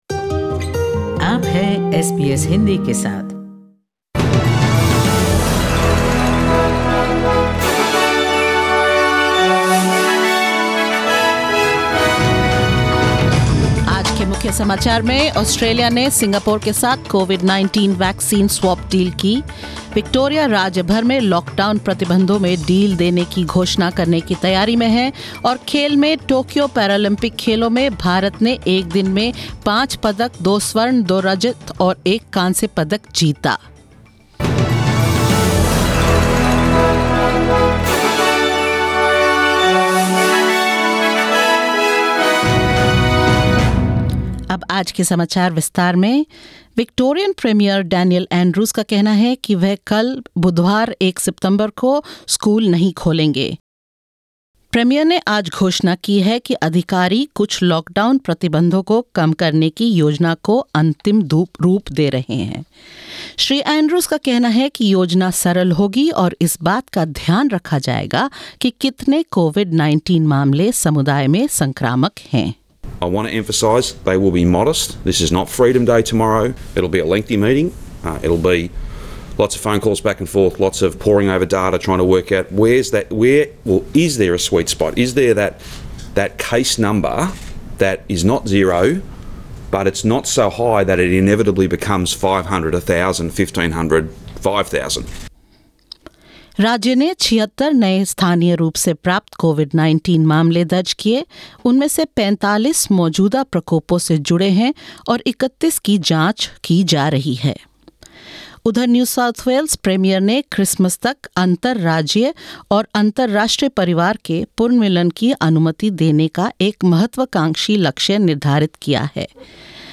In this latest SBS Hindi News bulletin of Australia and India: Australia announces a COVID-19 vaccine swap pact with Singapore; Victorian authorities plan to ease lockdown restrictions; NSW Premier sets ambitious goal to allow family reunions by Christmas; India bags 7 medals in Tokyo Paralympics and more.